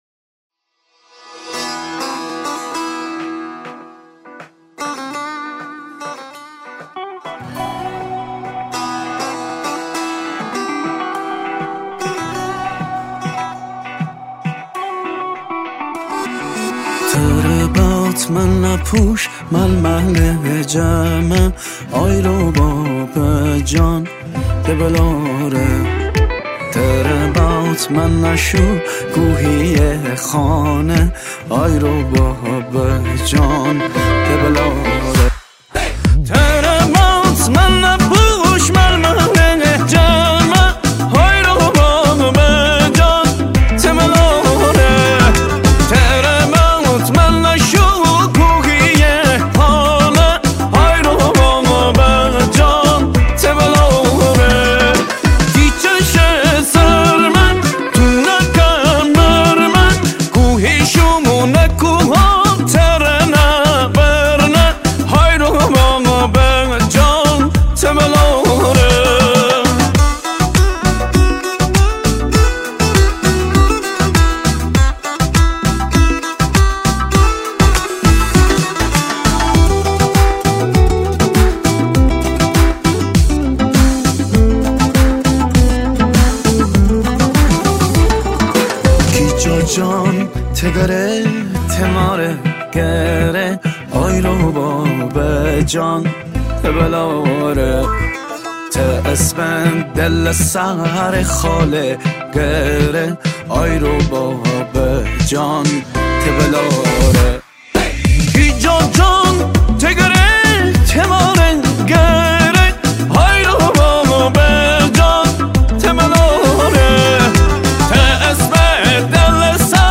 قطعه باکلام